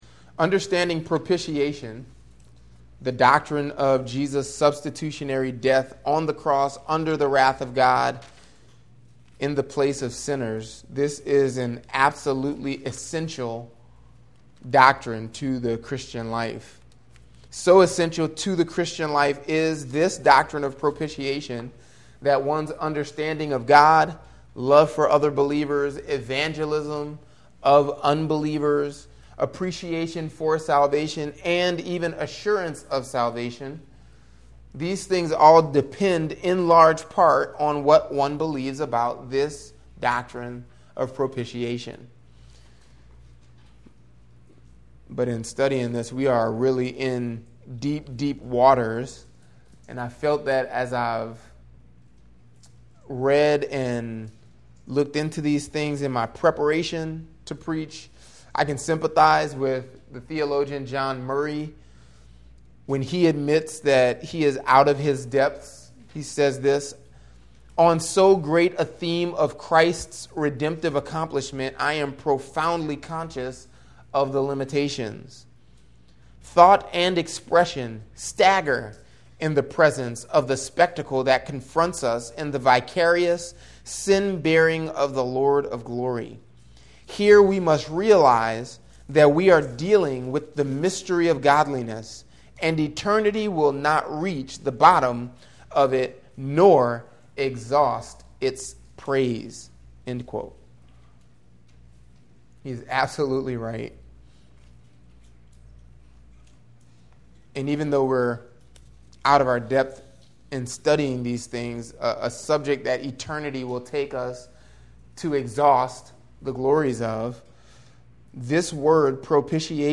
gbc-nola-sermons